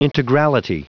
Prononciation du mot integrality en anglais (fichier audio)
Prononciation du mot : integrality